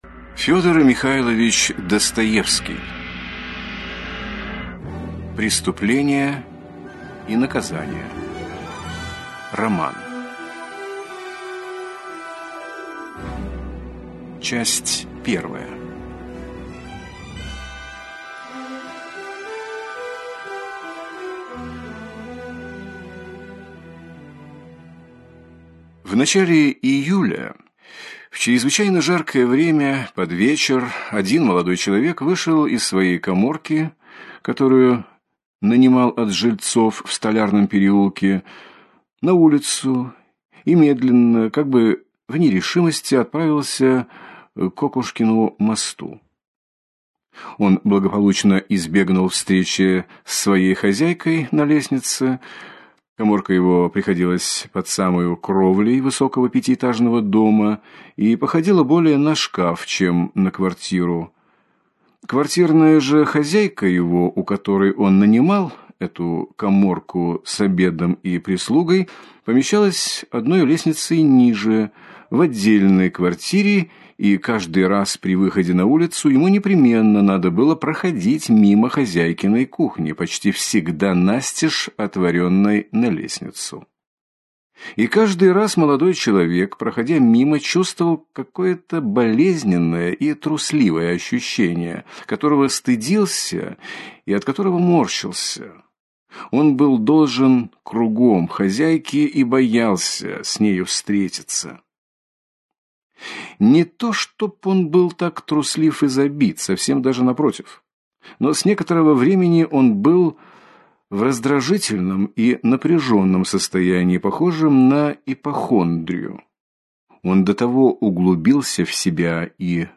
Аудиокнига Преступление и наказание - купить, скачать и слушать онлайн | КнигоПоиск